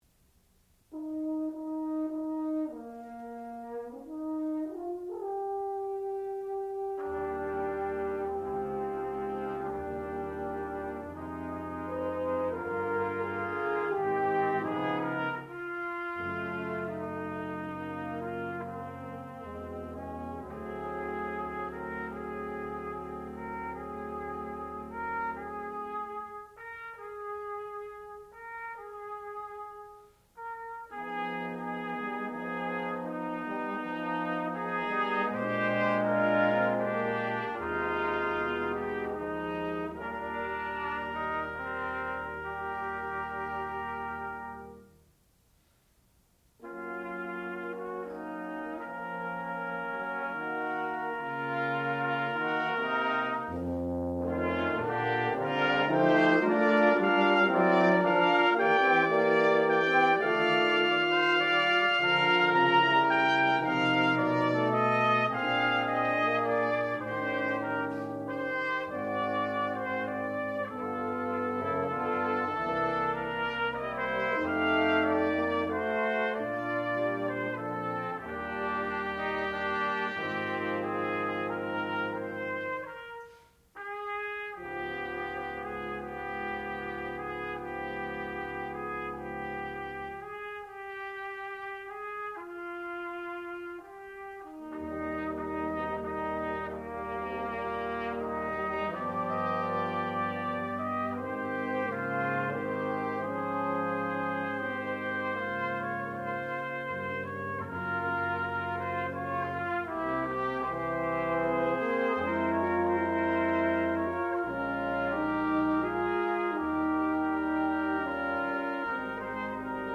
sound recording-musical
classical music
trumpet
tuba